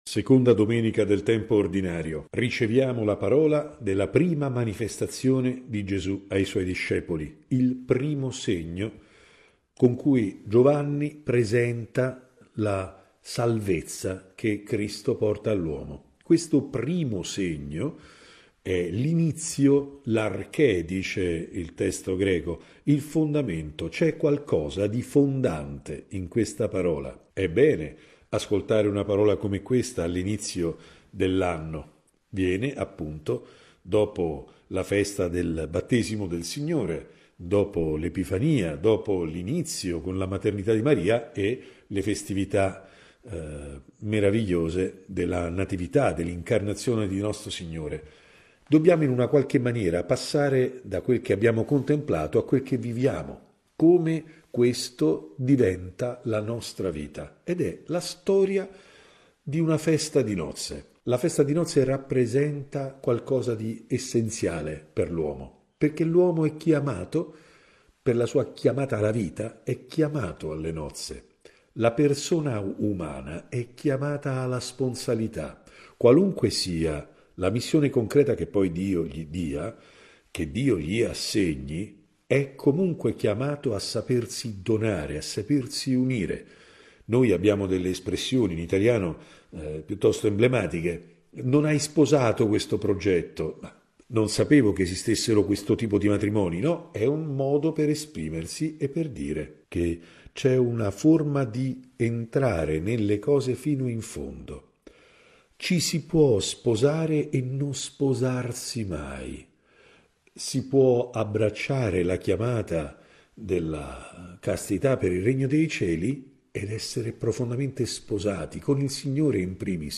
Seconda Domenica del tempo ordinario - Commento al Vangelo